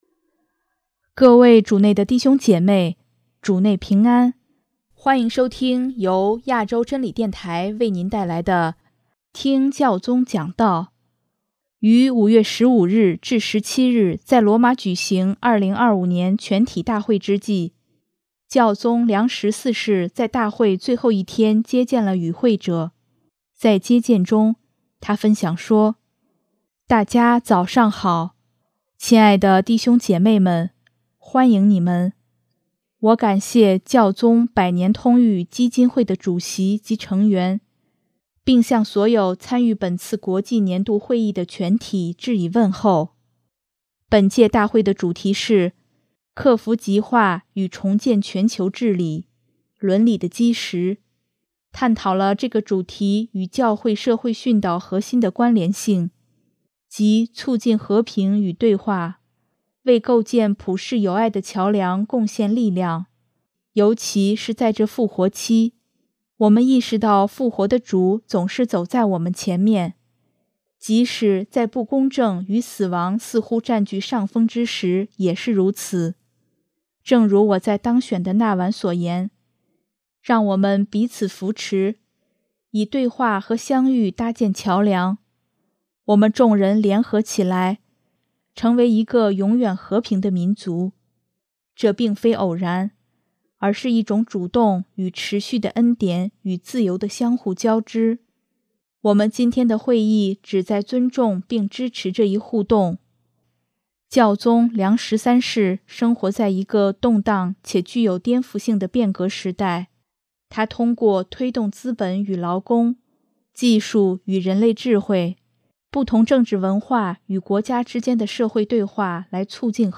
【听教宗讲道】|人们回应问题的方法，比问题本身更重要
于5月15日至17日在罗马举行2025年全体大会之际，教宗良十四世在大会最后一天接见了与会者。